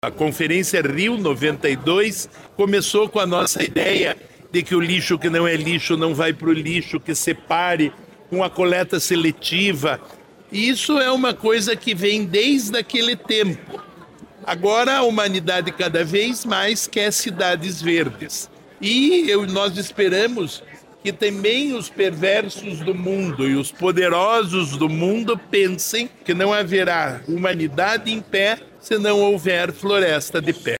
Sonora do secretário do Desenvolvimento Sustentável, Rafael Greca, sobre a Conferência da Mata Atlântica